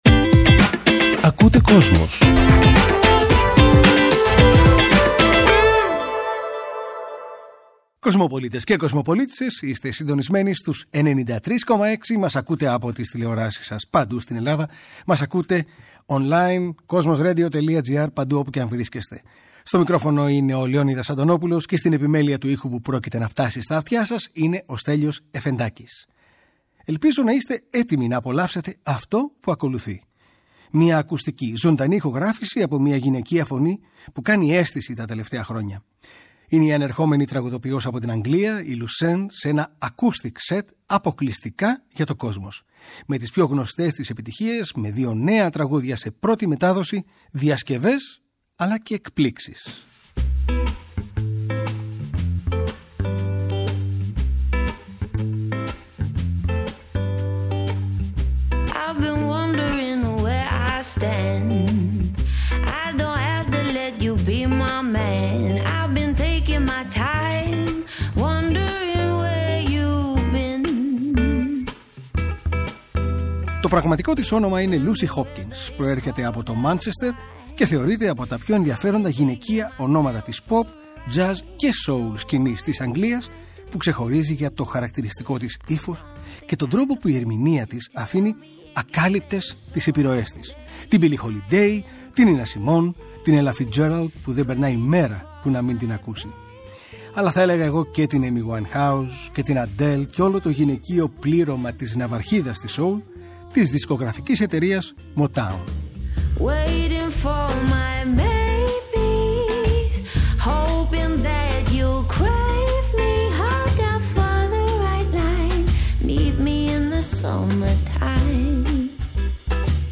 σε ένα acoustic set